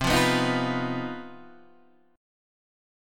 CmM7bb5 chord {x 3 1 4 1 1} chord